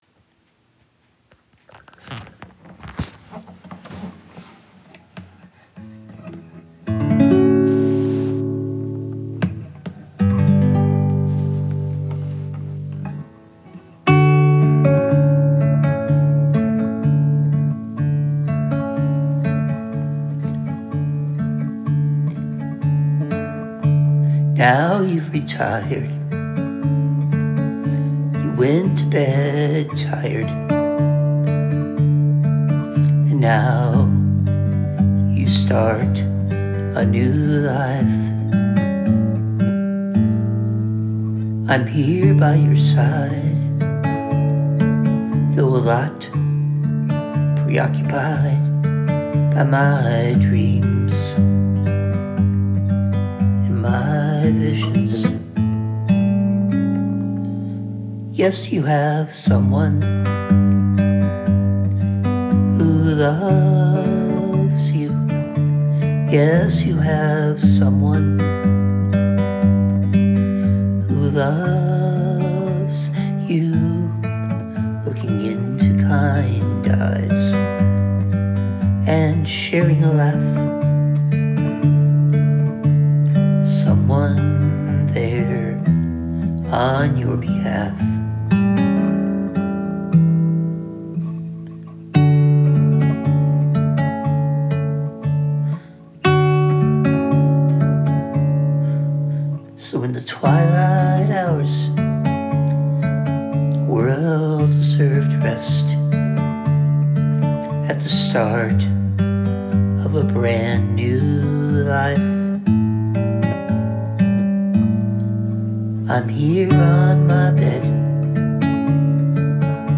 Love the finger picking and your gentle way of singing.